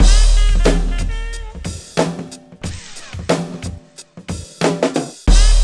BEAT 1 91 00.wav